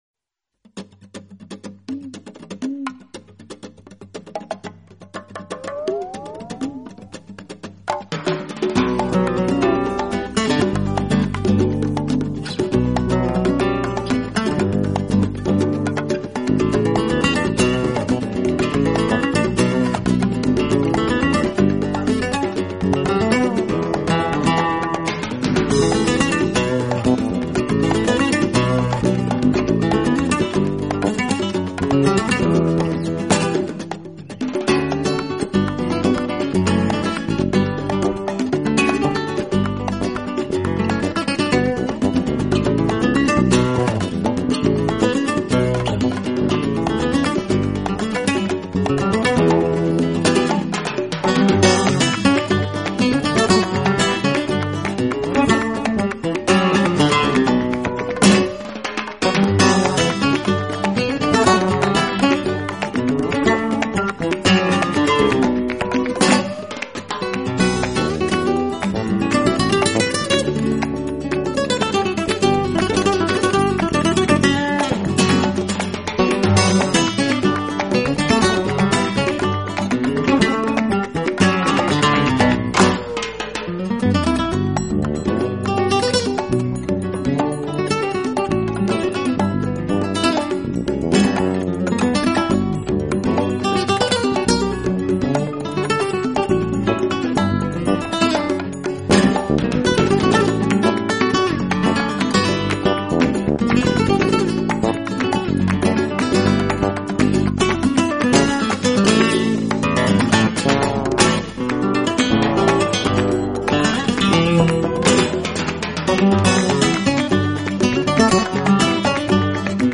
【吉他专辑】